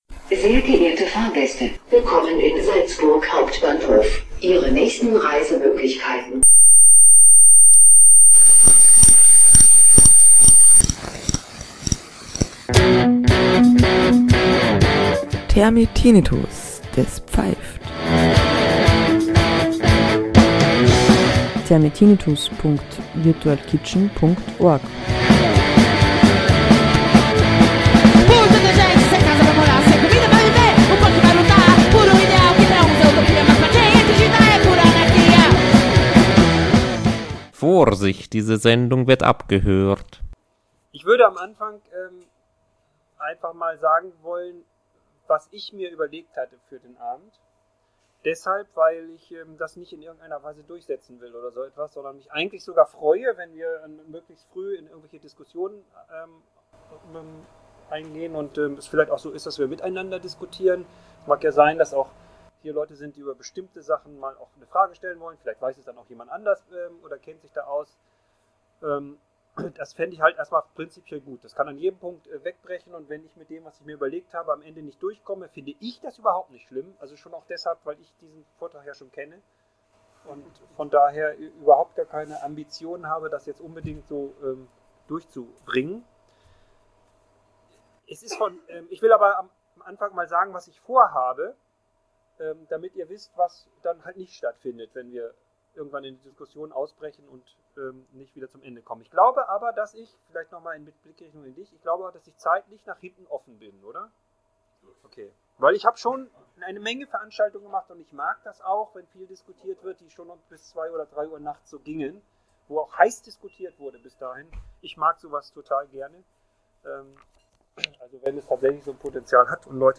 Vortrag: Den Kopf entlasten